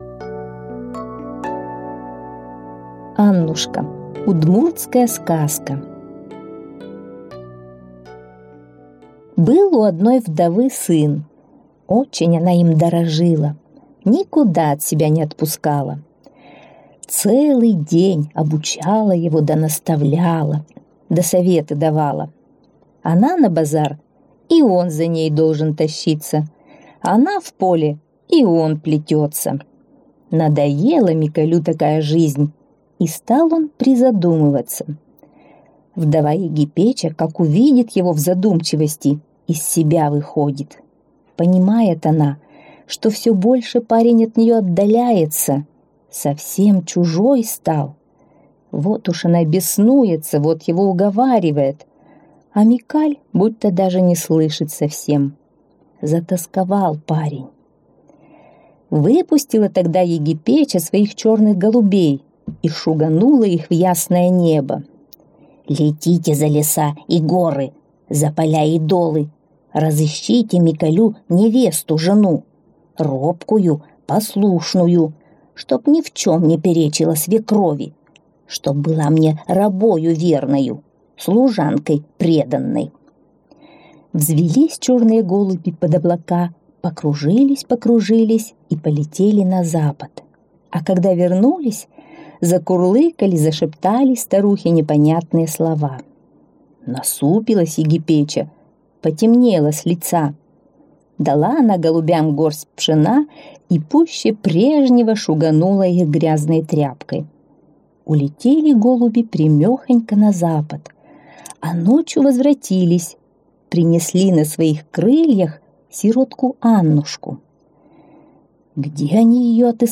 Аннушка - удмуртская аудиосказка - слушать онлайн